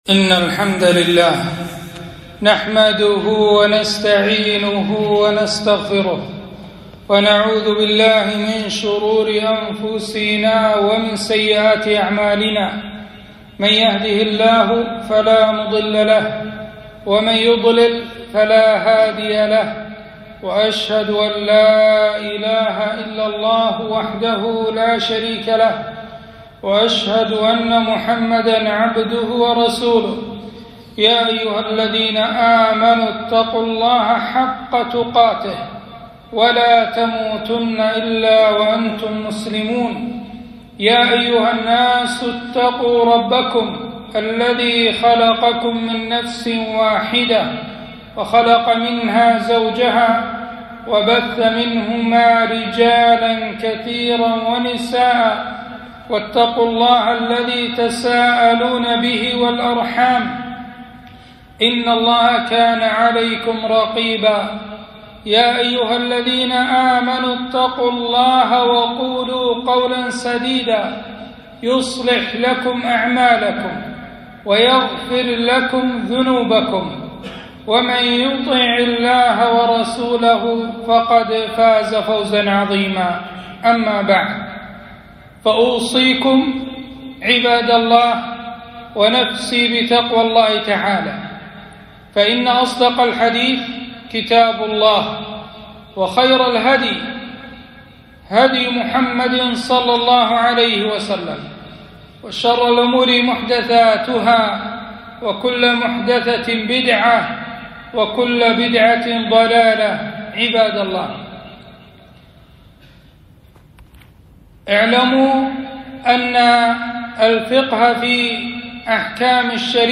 خطبة - أحكام اليمن بالله عز وجل